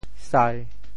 酾（釃） 部首拼音 部首 酉 总笔划 14 部外笔划 7 普通话 shī 潮州发音 潮州 sai1 文 中文解释 酾 <动> 滤[酒] [filter] 酾,下酒也。
sai1.mp3